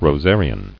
[ro·sar·i·an]